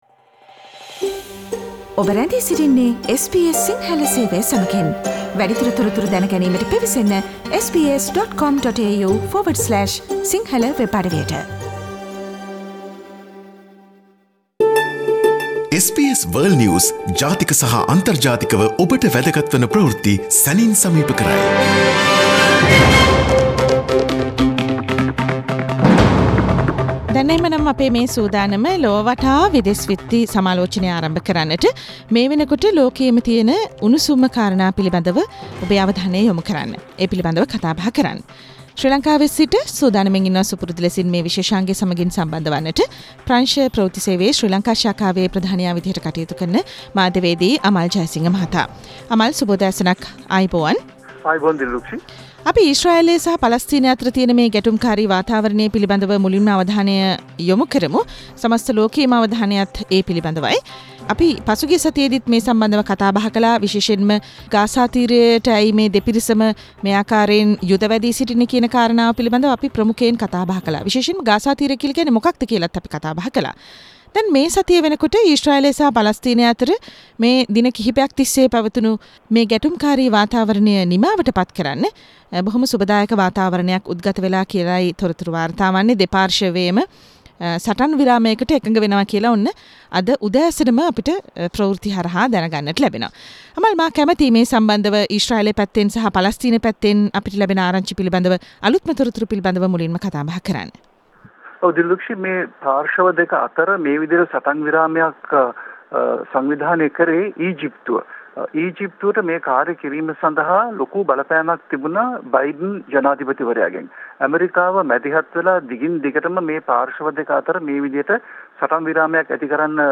Listen to the latest news from around the world this week from our weekly "Around the World" foreign news review.